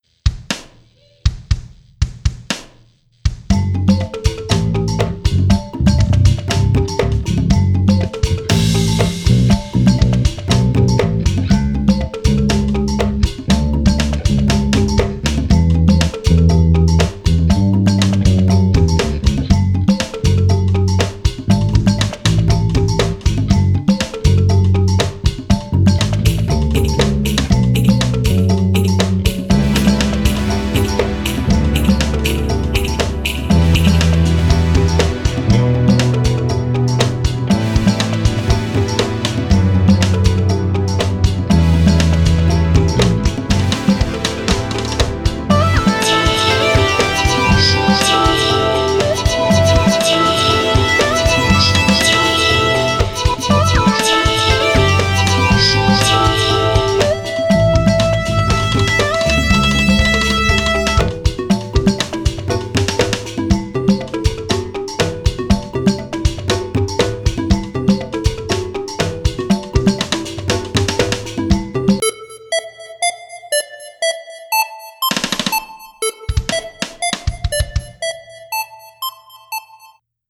Genre: Ambient, New Age, Meditative.